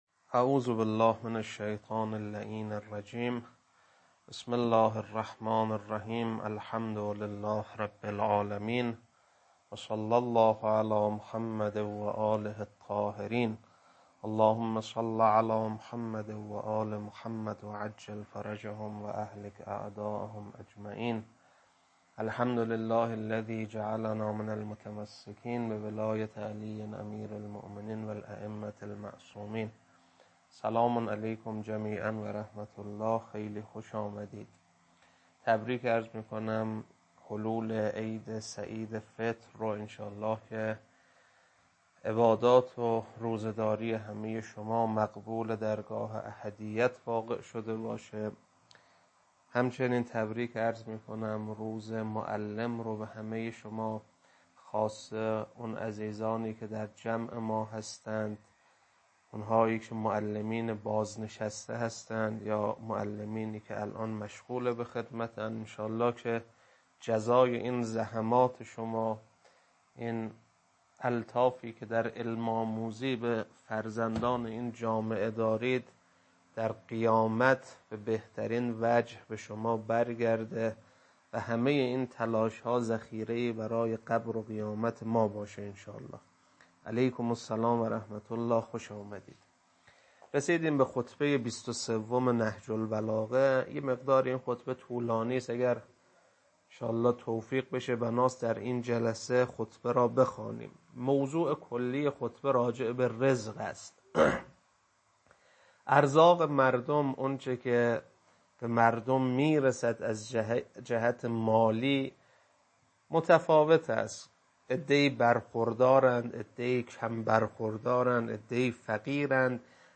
خطبه-23.mp3